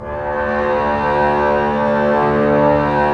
Index of /90_sSampleCDs/Roland LCDP13 String Sections/STR_Cbs FX/STR_Cbs Sul Pont